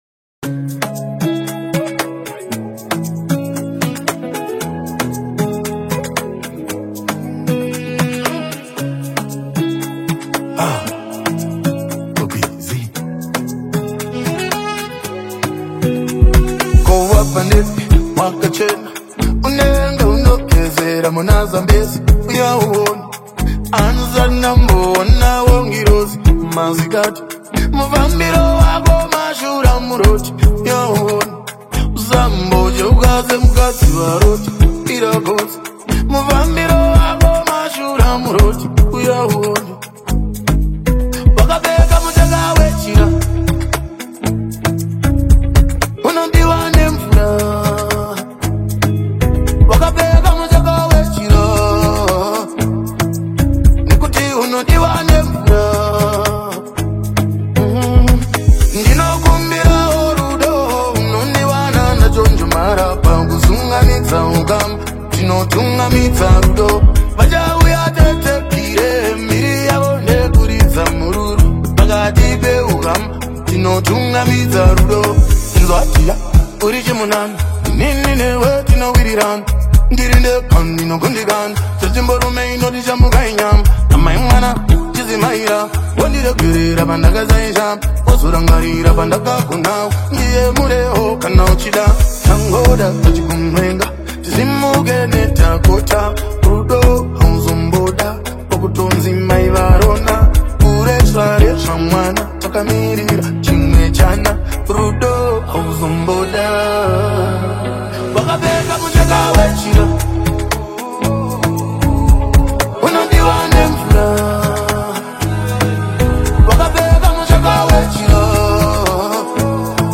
AudioR&BZimbabwe Music
heartfelt Afro-Pop/R&B single
soulful rhythms and rich storytelling